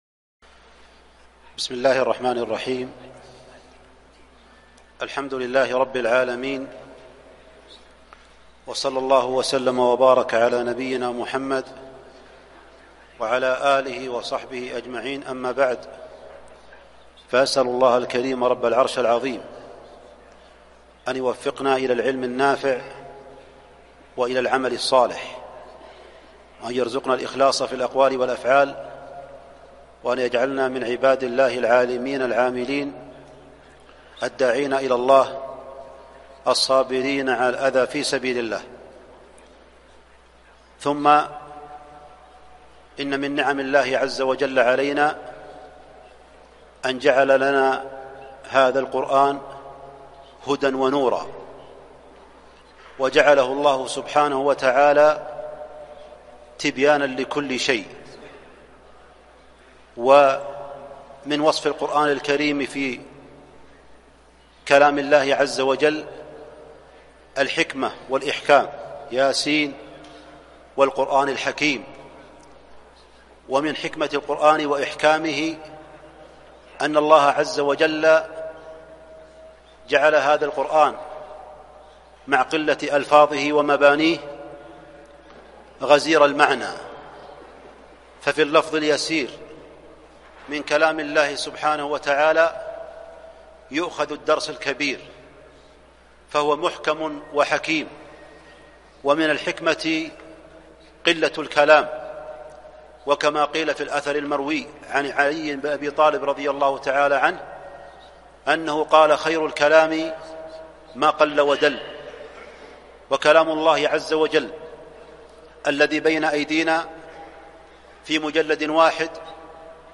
جامع الراجحي بحي الجزيرة بالرياض 1441